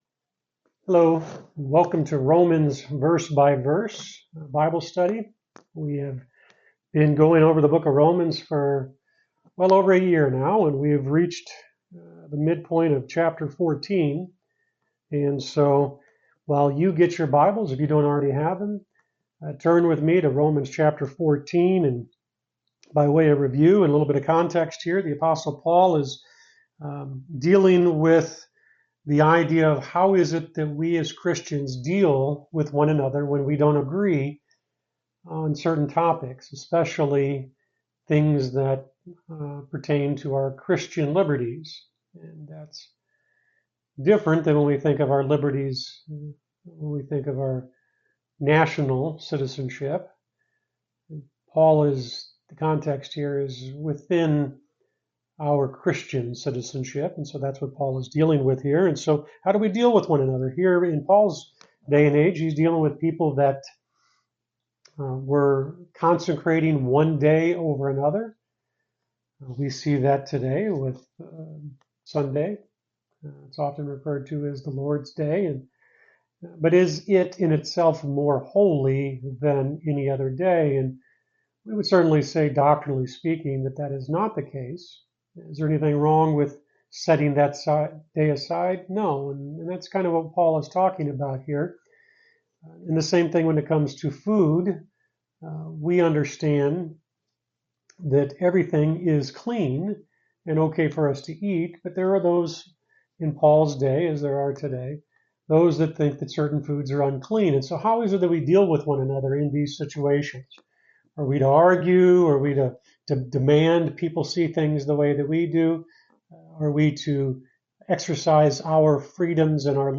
You're listening to Lesson 86 from the sermon series "Romans